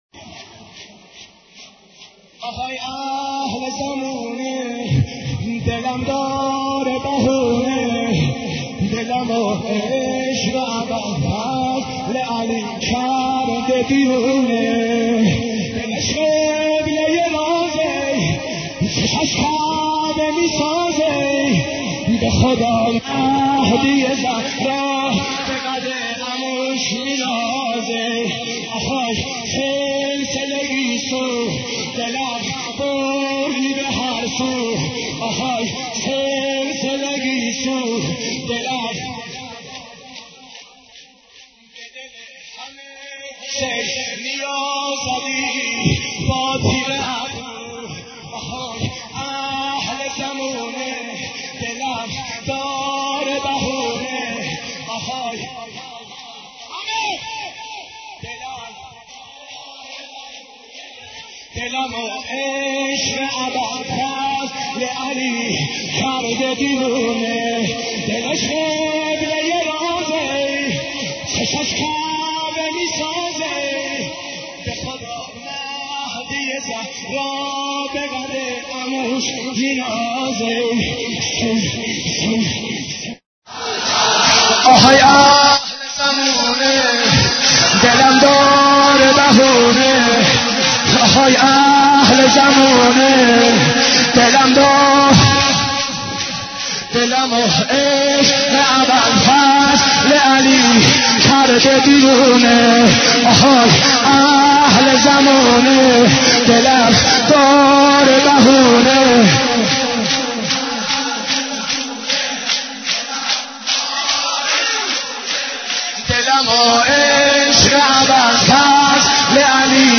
حضرت عباس ع ـ شور 35